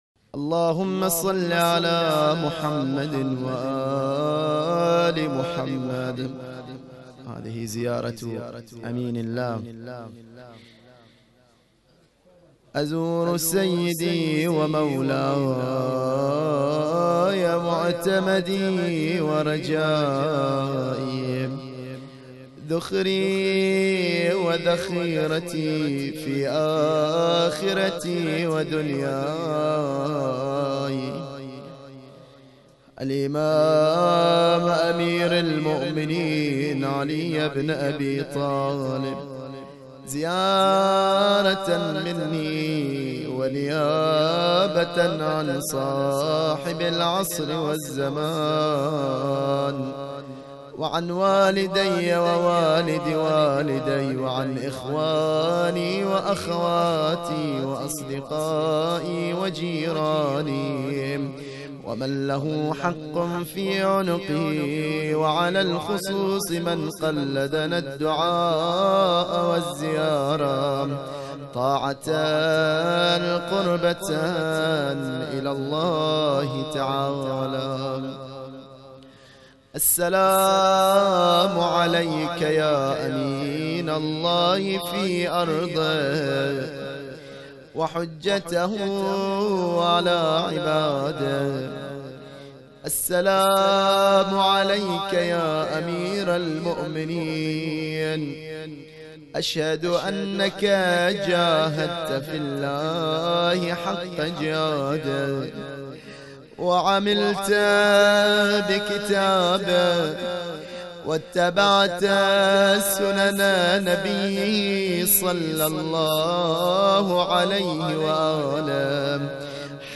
الرادود